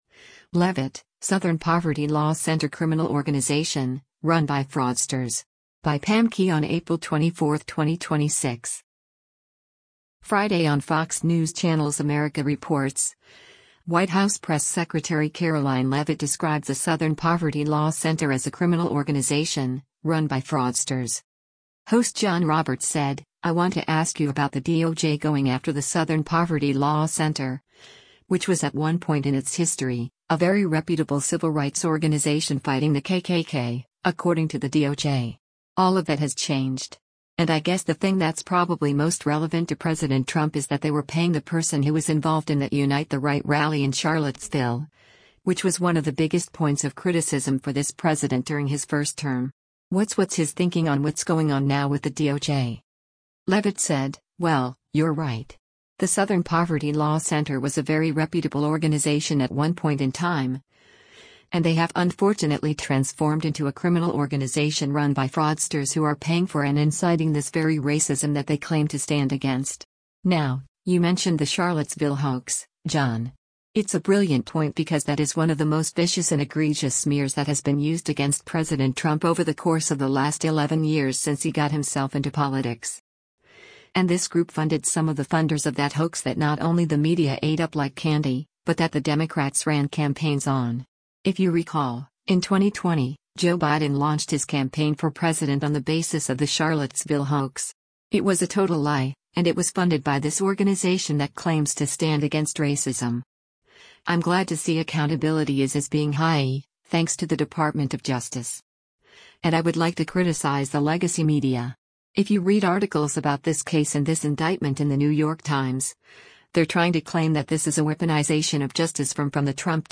Friday on Fox News Channel’s “America Reports,” White House press secretary Karoline Leavitt described the Southern Poverty Law Center as a “criminal organization, run by fraudsters.”